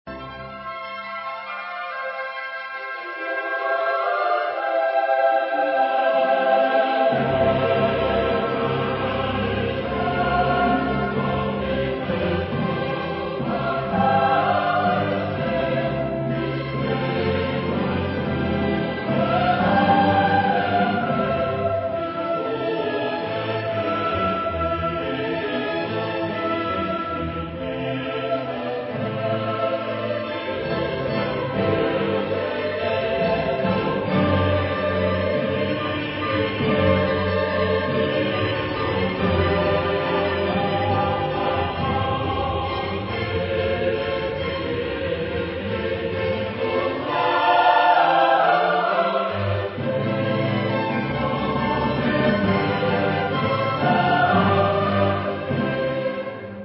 Genre-Stil-Form: geistlich ; Kantate ; Cantus-firmus Komposition
Chorgattung: SSATB  (4 gemischter Chor Stimmen )
Solisten: Sopran (1) / Bariton (1)
Instrumentation: Orchester  (6 Instrumentalstimme(n))
Instrumente: Oboe (1) ; Violinen (2) ; Viola (1) ; Fagott (1) ; Violoncello (1) ; Kontrabaß (1) ; Pauken (2)
Tonart(en): C-Dur
von Kammerchor Stuttgart gesungen unter der Leitung von Frieder Bernius